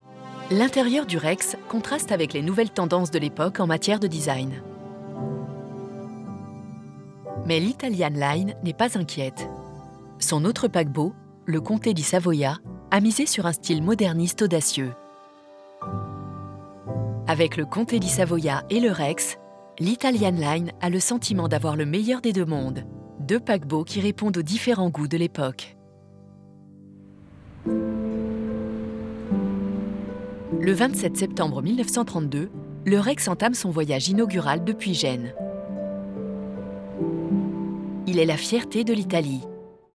Narration
Narration Histoire voix chaleureuse voix douce voix élégante Narration Catégories / Types de Voix Extrait : Votre navigateur ne gère pas l'élément video .